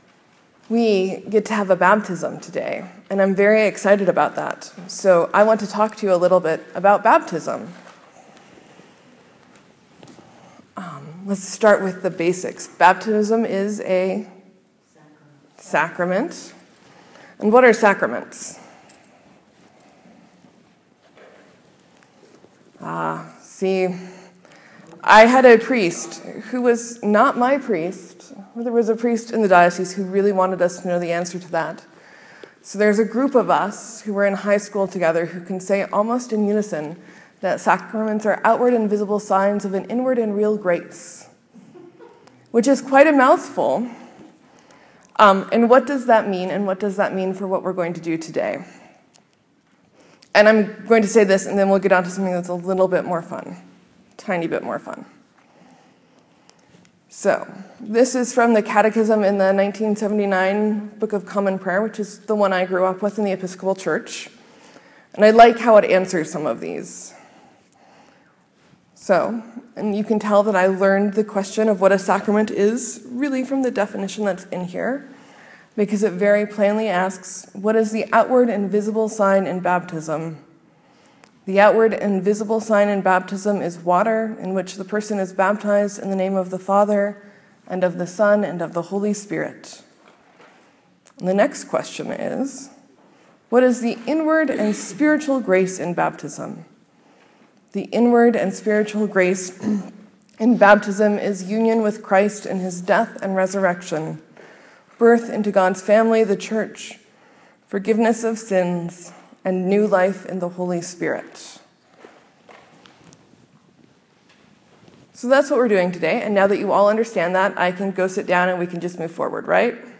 Sermon: This morning we’ll pray the Thanksgiving over Water, one of my favourites.